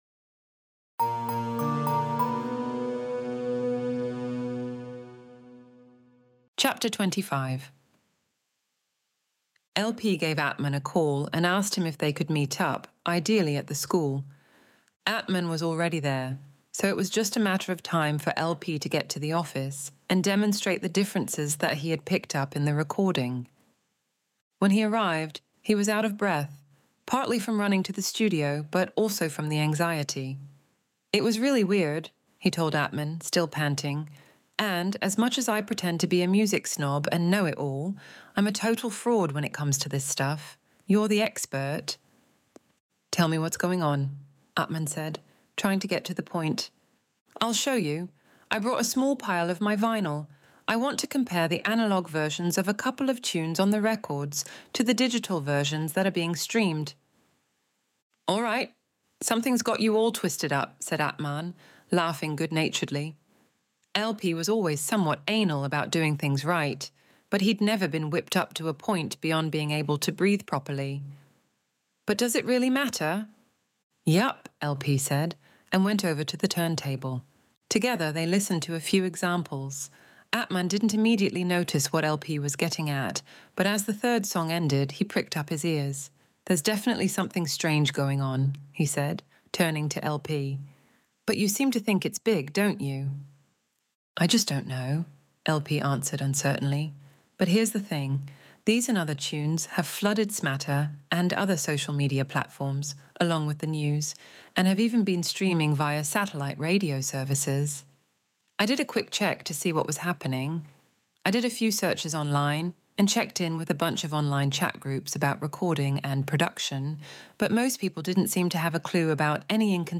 Extinction Event Audiobook Chapter 25